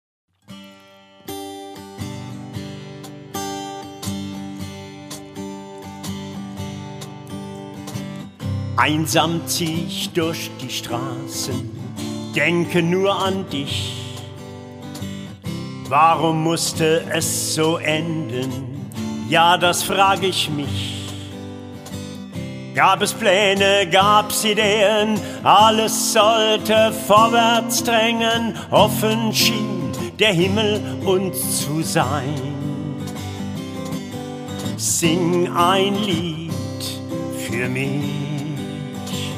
Authentische Lieder eines Abenteurers und Straßenmusikers.